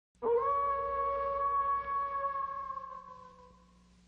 جلوه های صوتی
دانلود صدای زوزه گرگ 1 از ساعد نیوز با لینک مستقیم و کیفیت بالا